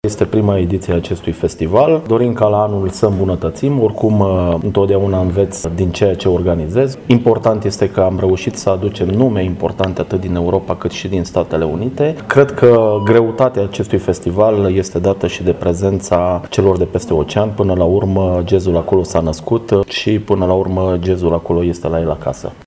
Va fi o manifestare de excepție, care contează pe prezența unor artiști de renume în acest domeniu, din Europa dar și de peste Ocean, adică din patria jazzului, după cum ne-a spus viceprimarul Brașovului, Mihai Costel: